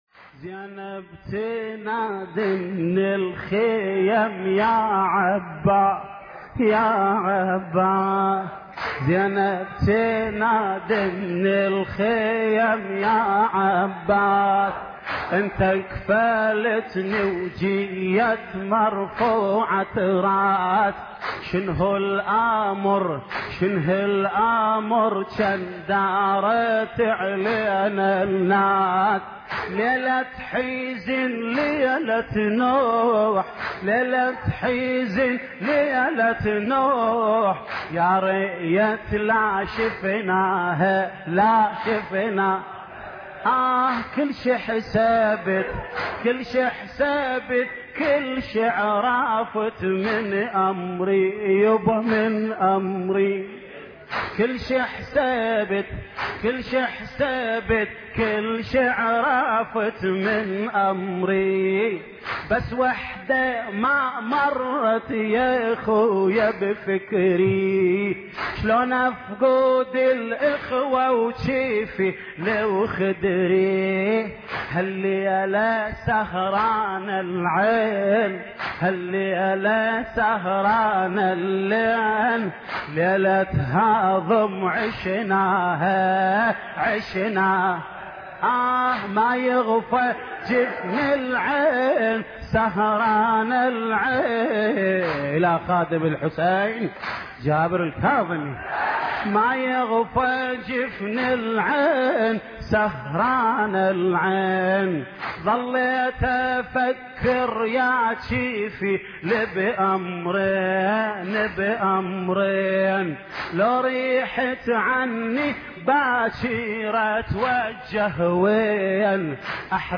مراثي زينب الكبرى (س)